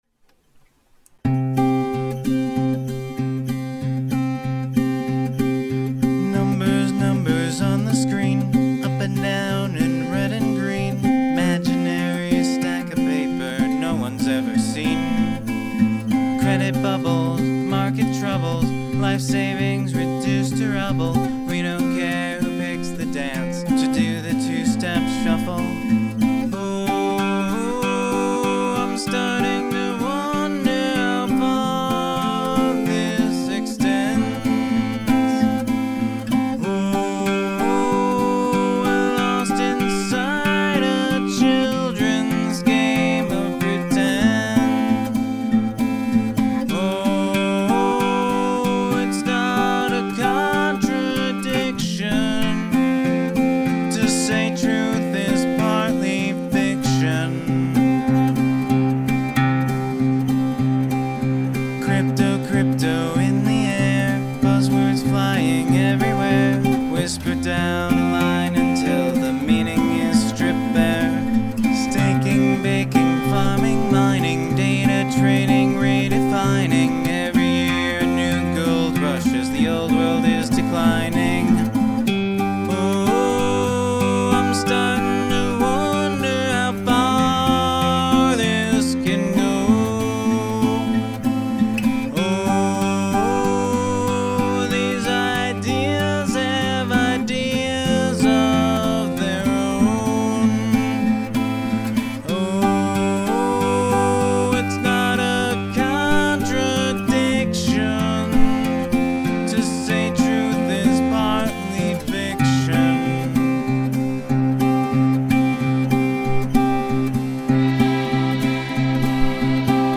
List Song